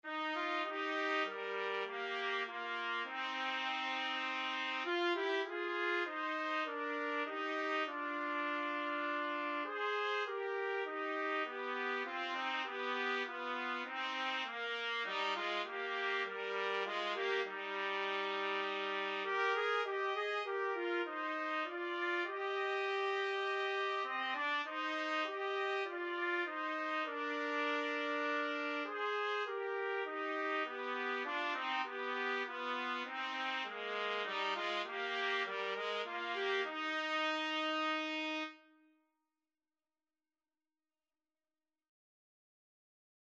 4/4 (View more 4/4 Music)
Classical (View more Classical Trumpet Duet Music)